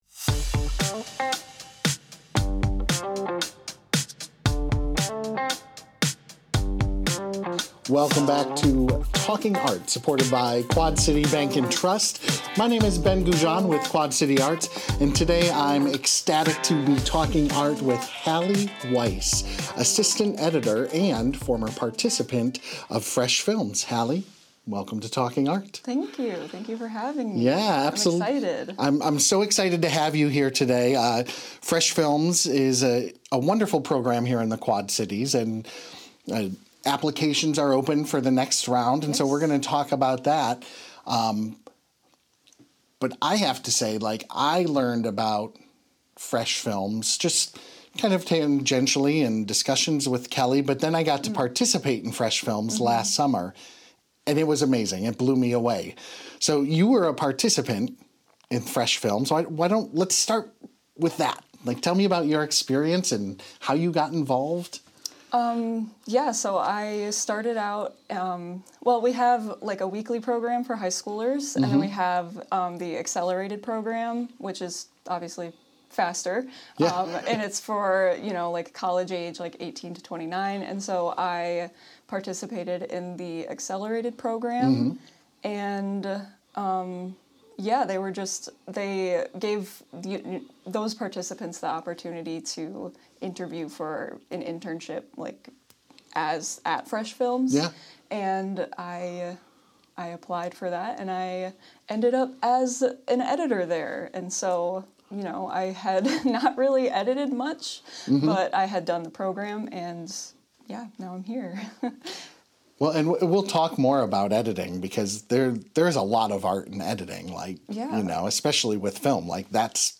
The conversation explores how early access to film education helps young artists develop both technical ability and creative voice.